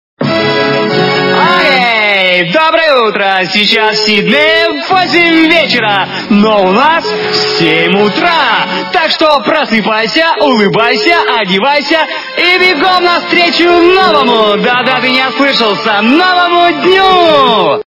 - Будильники